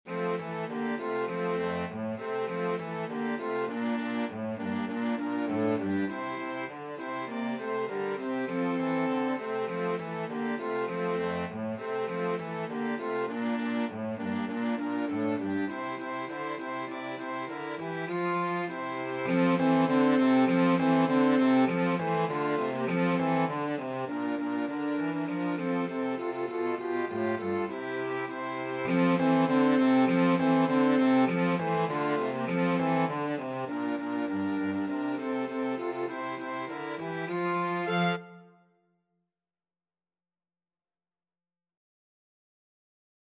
Christmas
Flexible Mixed Ensemble - 3 Players
Player 1 Trumpet Player 2 Alto Saxophone Player 3 Trombone